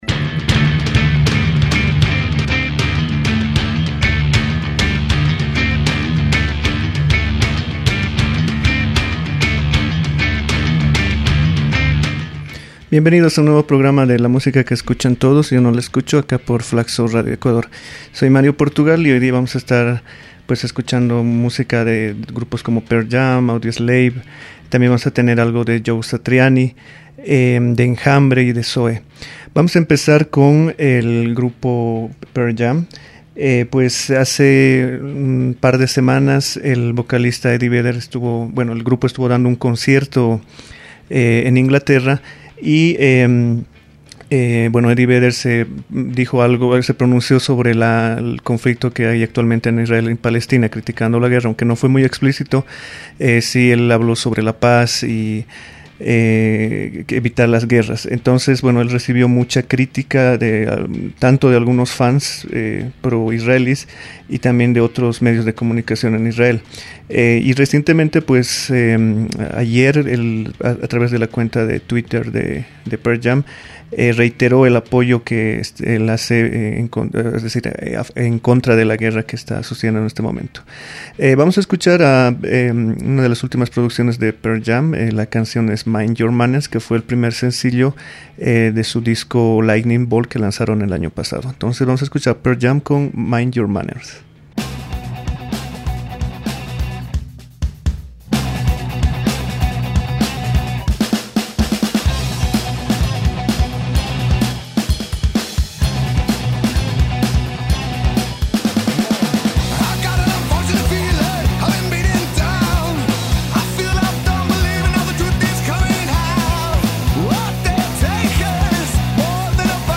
rock en español